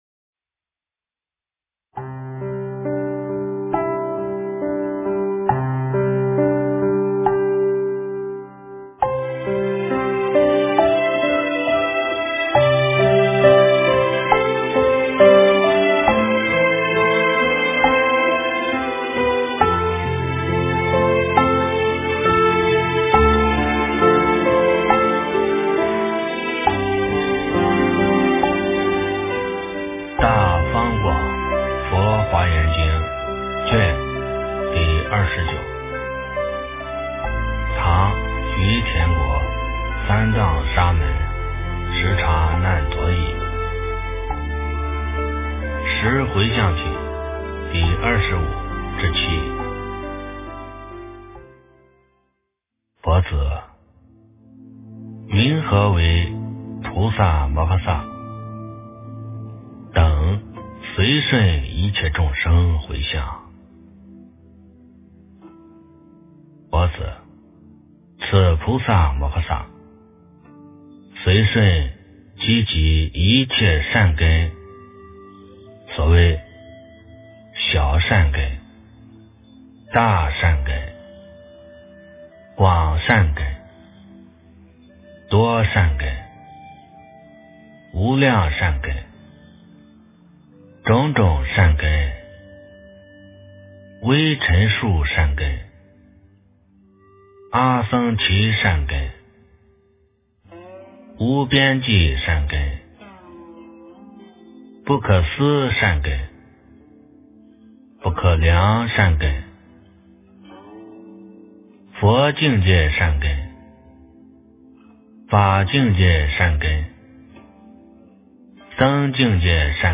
《华严经》29卷 - 诵经 - 云佛论坛